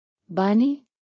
Bannu_Soft_Pashto.wav.ogg